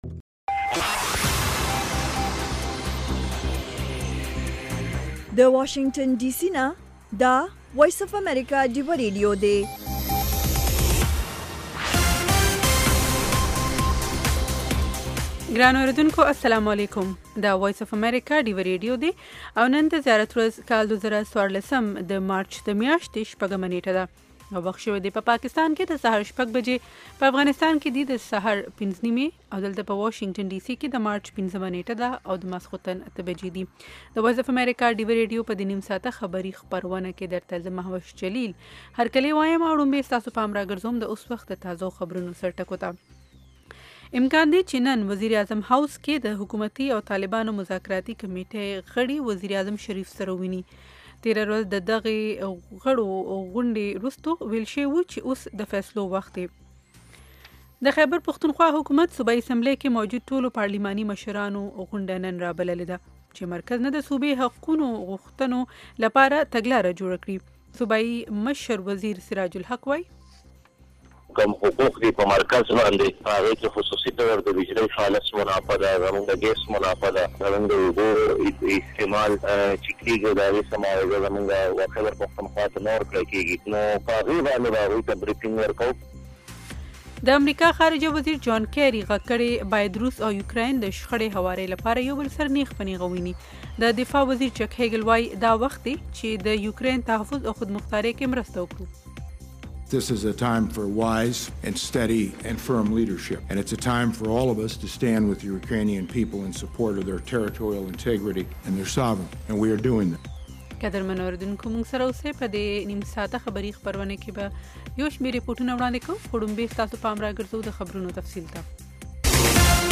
خبرونه - 0100